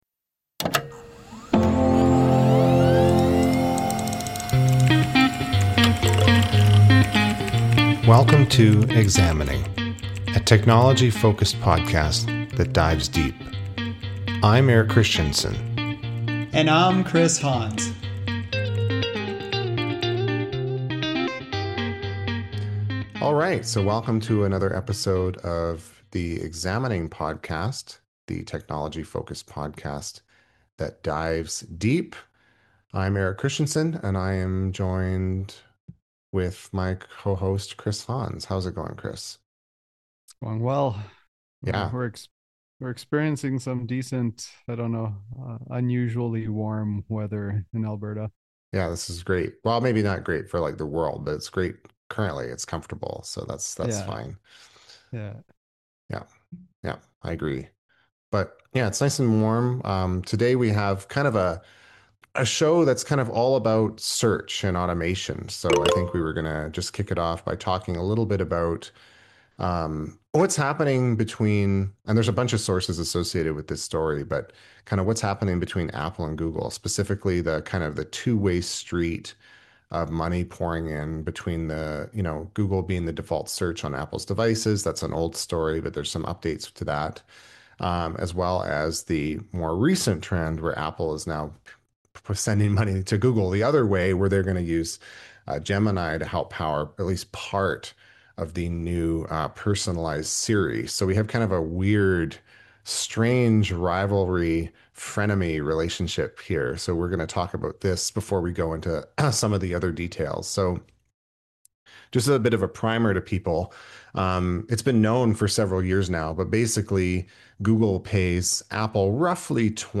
Additionally, they explore how AI is changing the game for search behaviors and user expectations, citing a study from the NN Group. The co-hosts also share their personal experiences with using AI (over the long term) to boost productivity and reduce research friction.